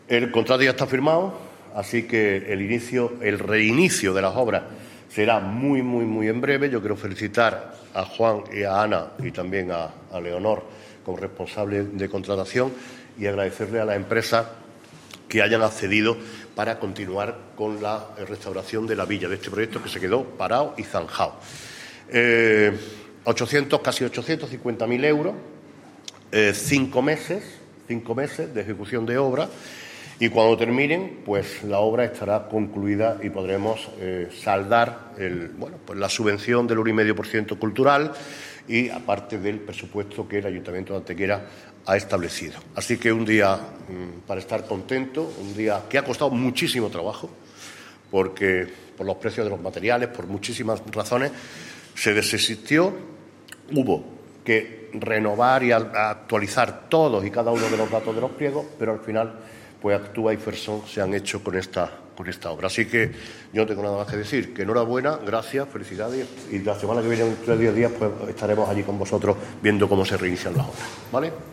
El alcalde de Antequera, Manolo Barón, el teniente de alcalde delegado de Contratación, Juan Rosas, y la teniente de alcalde de Patrimonio Histórico, Ana Cebrián, han comparecido hoy en rueda de prensa para anunciar la inminente continuación de las obras de recuperación y rehabilitación de la Villa Romana de la Estación, considerada uno de los principales vestigios arqueológicos de época romana en Andalucía.
Cortes de voz